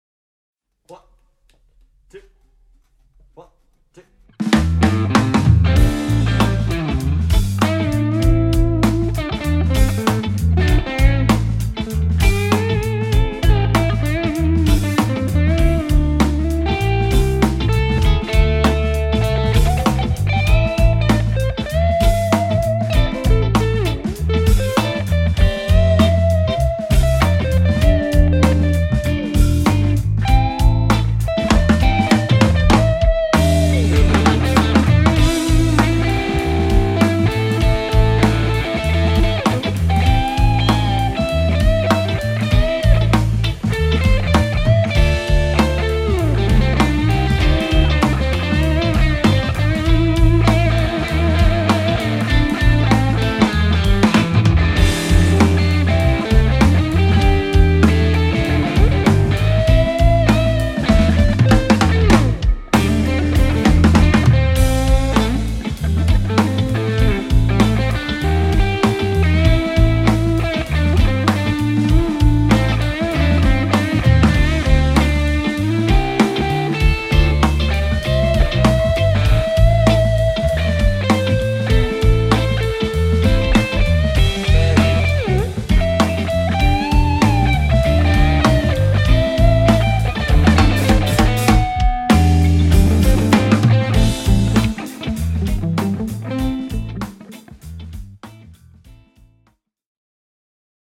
- Soita annetun taustan päälle solistinen osuus valitsemallasi instrumentilla
hyvin väpäjää
melko vapaata rytmiikkaa, hyvät soundit ja ok perusharmoniat
Rouheaa voimallista meininkiä. Rytmikkäässeen taustaan nähden kontrastia rytmipuolessa.